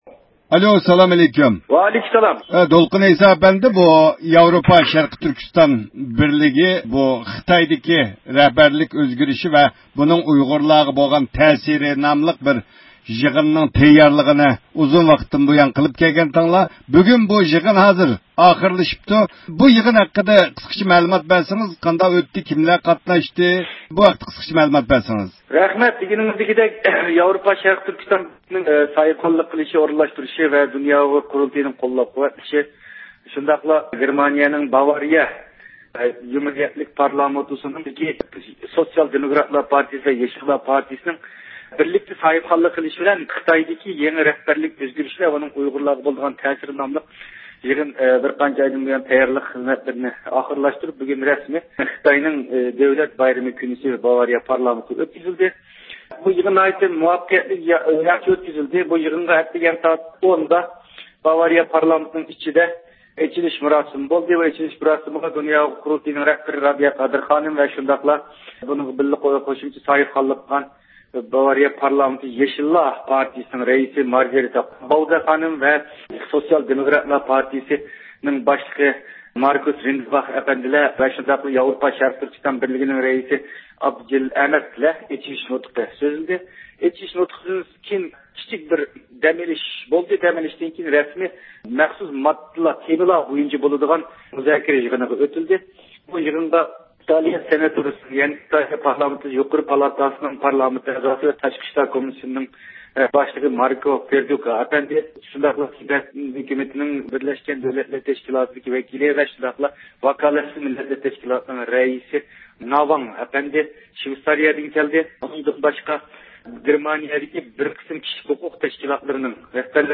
بۇ يىغىن ھەققىدە تېخىمۇ تەپسىلىي مەلۇمات ئېلىش ئۈچۈن بۇ يىغىننى ئۇيۇشتۇرغۇچى دولقۇن ئەيسا ئەپەندى بىلەن سۆھبەت ئېلىپ باردۇق.